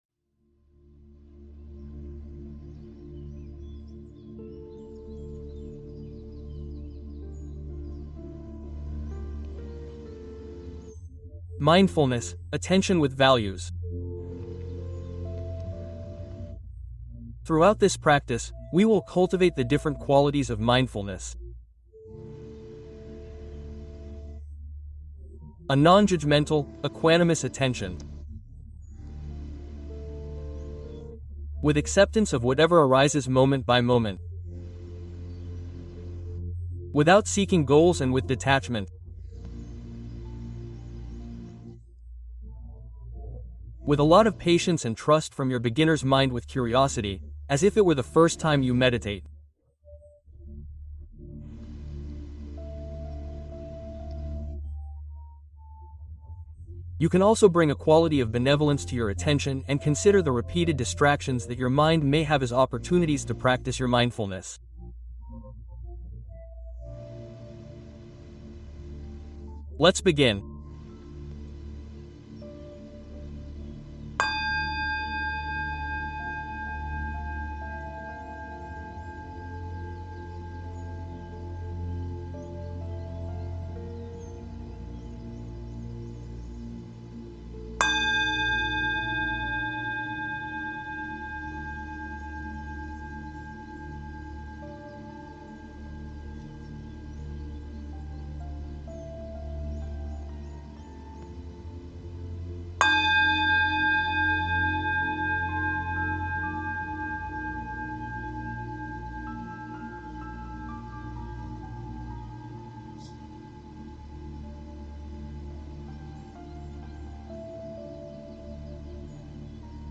Mindfulness con valores: meditación guiada para vivir con propósito